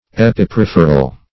Epiperipheral \Ep`i*pe*riph"er*al\, a. [Pref. epi- +